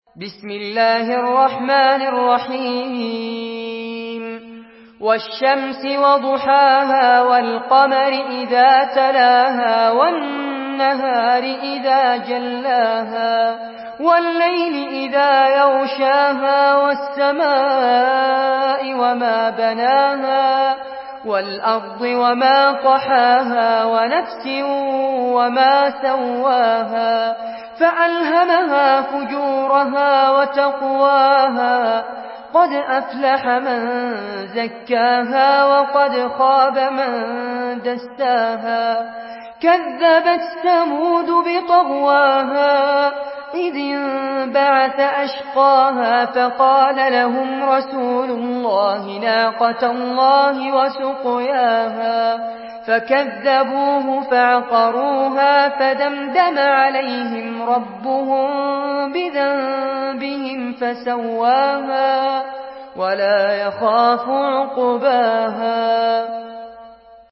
Surah Ash-Shams MP3 by Fares Abbad in Hafs An Asim narration.
Murattal